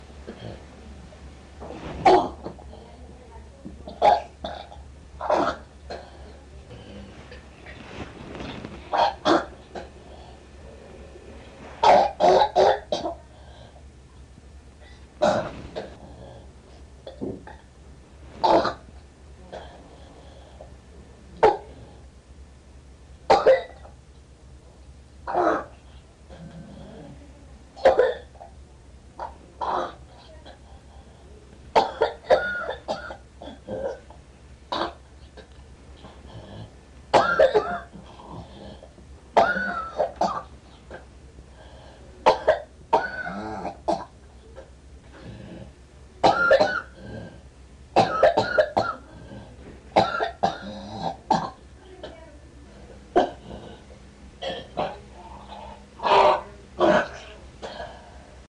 Descarga de Sonidos mp3 Gratis: vomito 1.
vomito-2.mp3